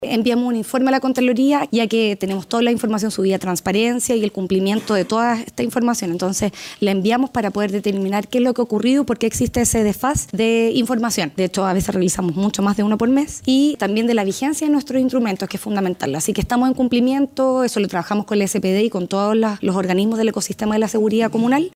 La misma alcaldesa de la Ciudad Jardín, Macarena Ripamonti, explicó en la última sesión del Concejo Municipal la situación de la comuna, argumentando que existe un desfase en la información.